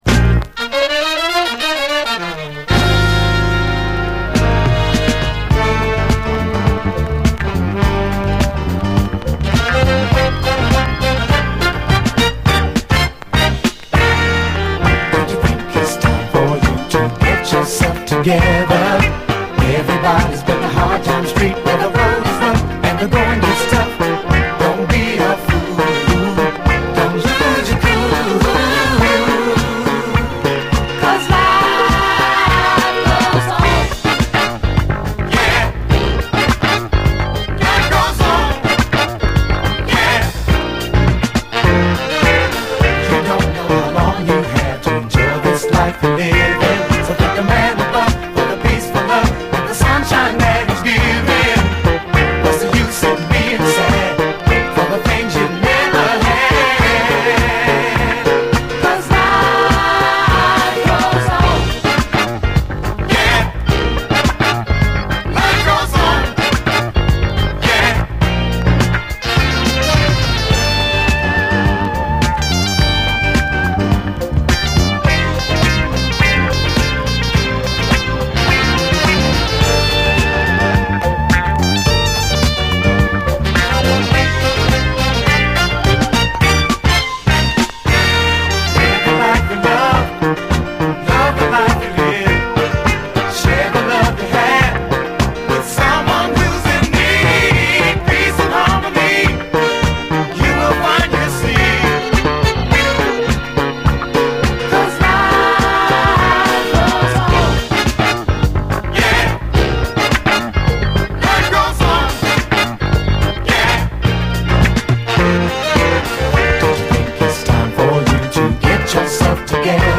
盤見た目少しスレありますが実際は概ね綺麗に聴けます。
MONO